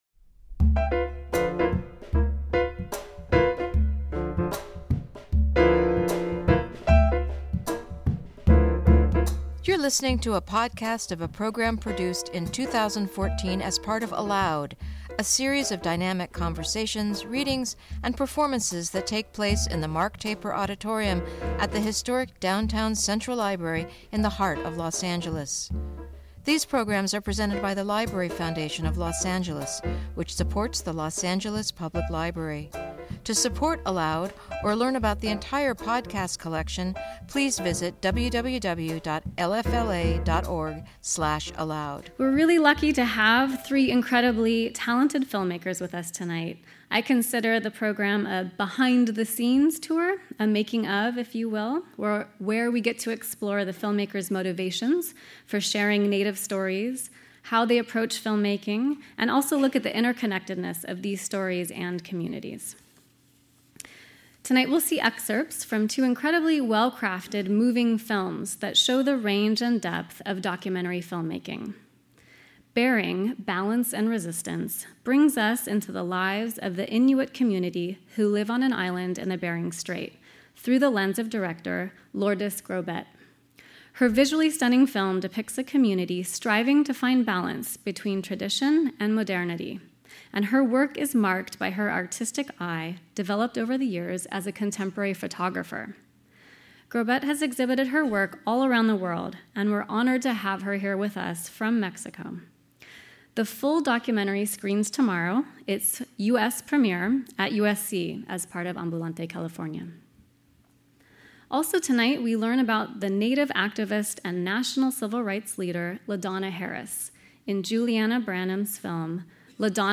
Two filmmakers share and discuss excerpts from their new documentaries that illuminate indigenous stories rarely seen on film.
In conversation